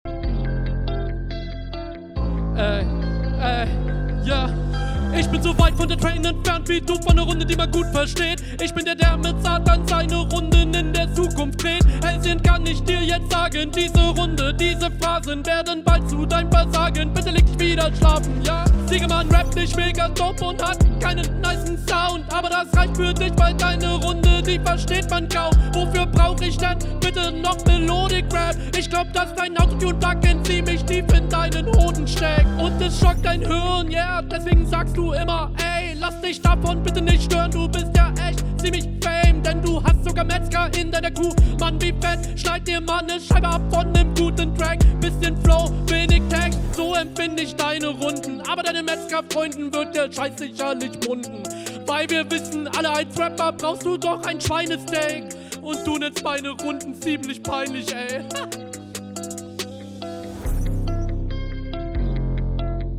Bro du flowst ja mal mega lässig als Einstieg!